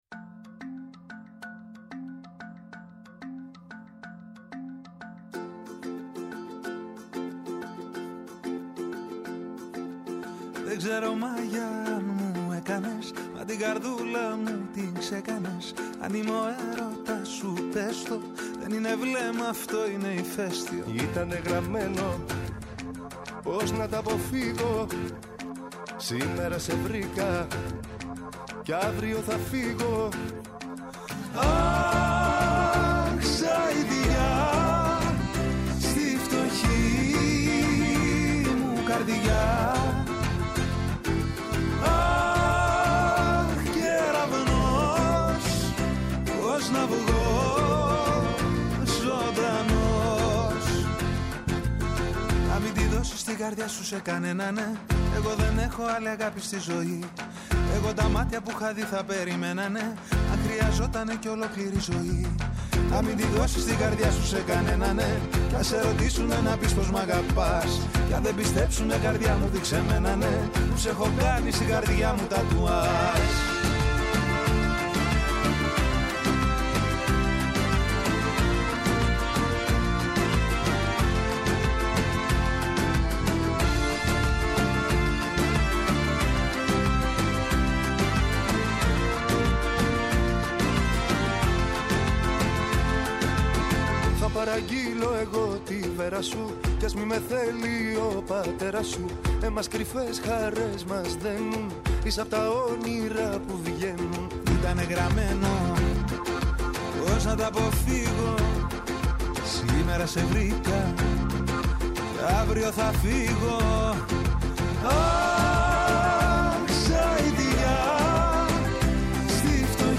Καλεσμένοι τηλεφωνικά σήμερα